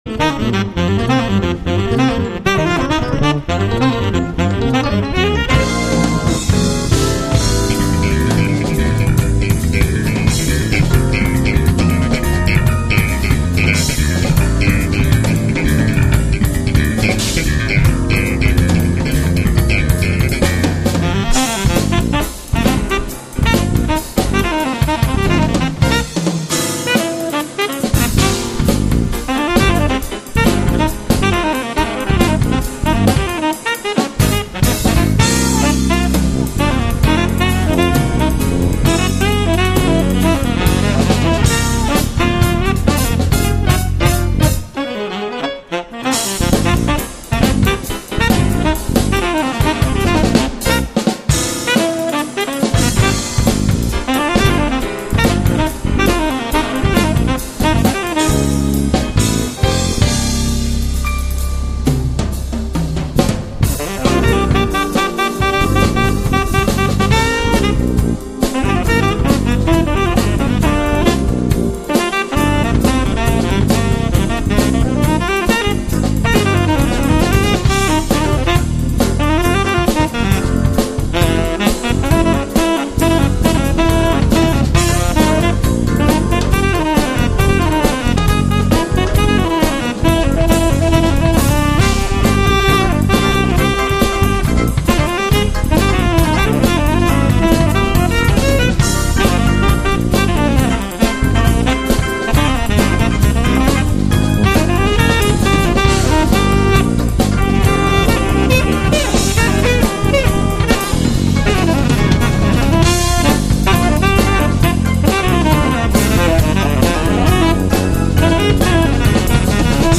Instrumental Jazz
Música popular: instrumental y jazz